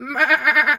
sheep_2_baa_calm_05.wav